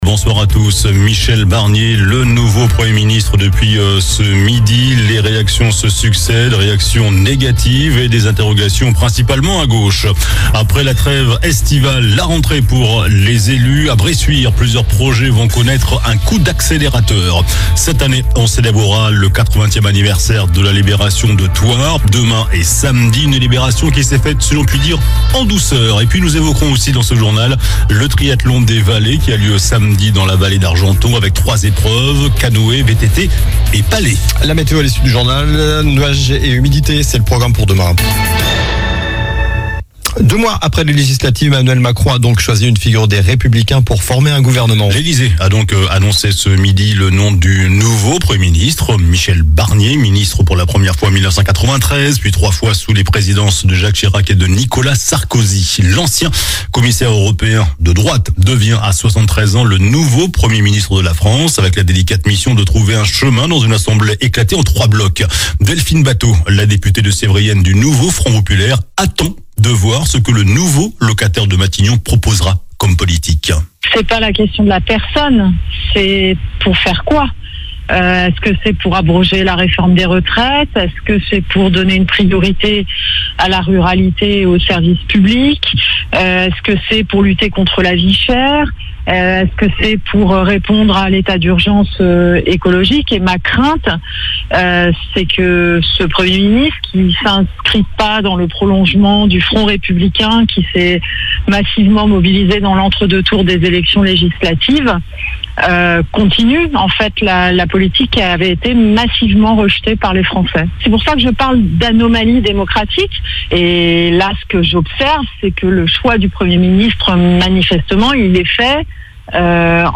JOURNAL DU JEUDI 05 SEPTEMBRE ( SOIR )